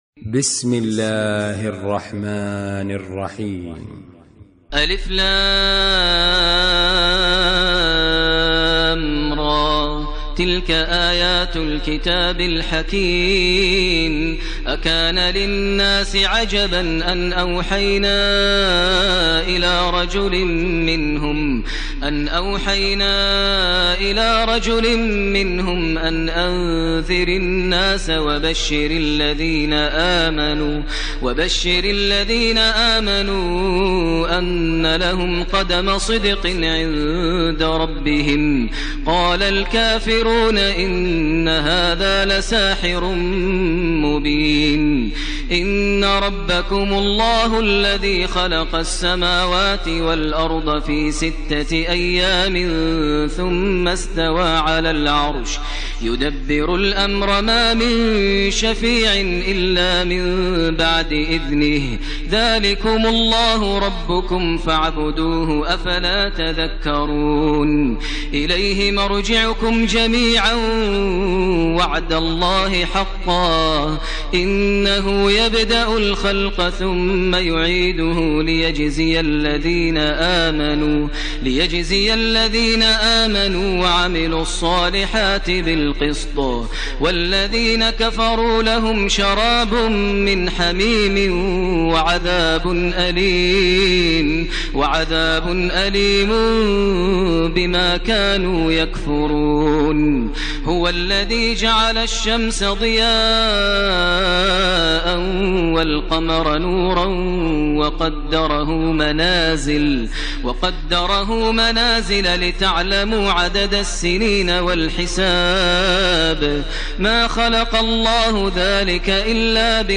ترتیل سوره یونس با صدای ماهر المعیقلی
010-Maher-Al-Muaiqly-Surah-Yunus.mp3